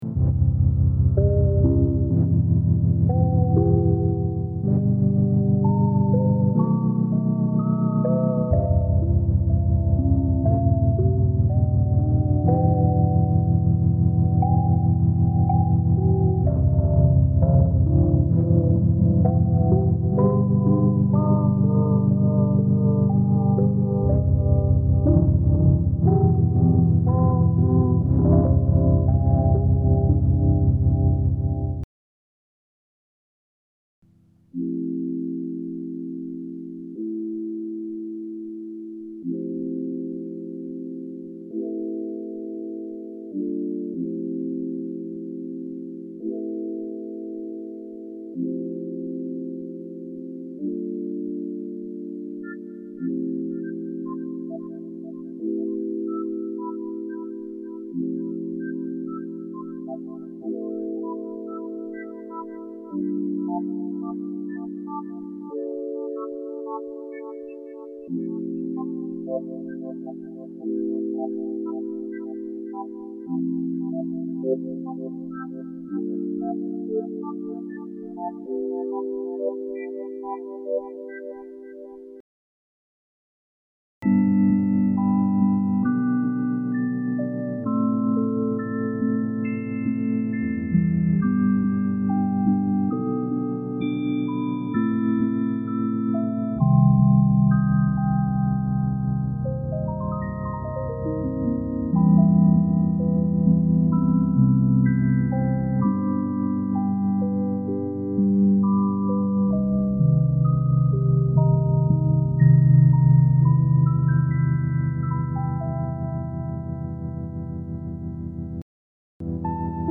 A pocket-sized musical instrument .
audio_demo.mp3